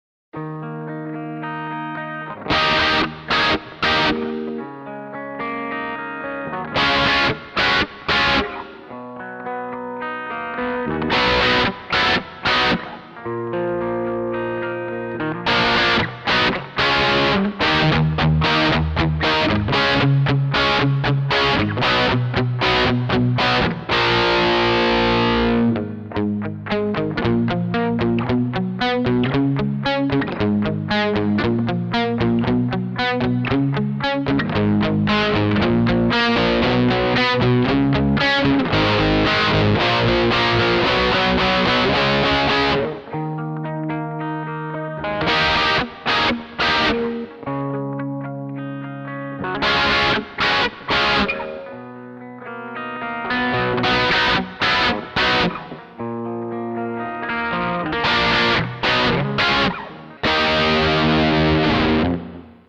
Here is a quick clip of my Carvin Legacy. I pick softly then hard to show how this amp responds to dynamic picking. No post processing was done other than adding a bit of reverb.